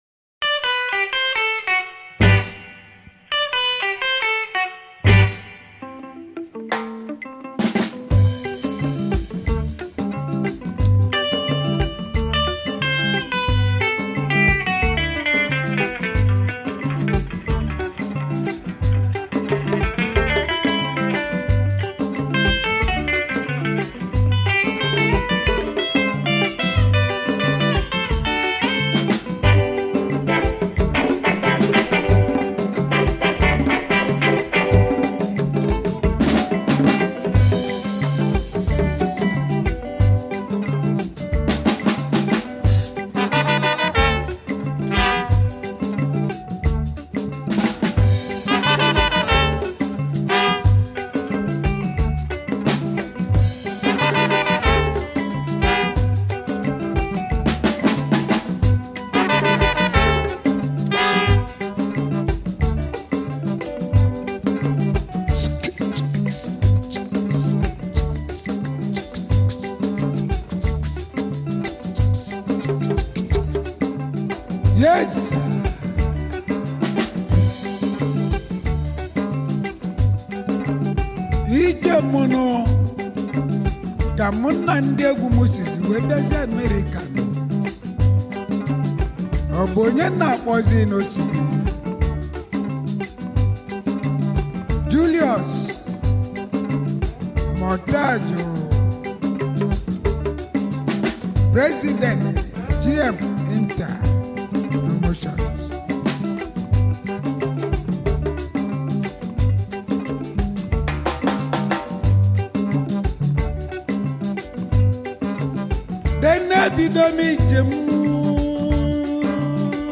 September 10, 2024 admin Highlife Music, Music 0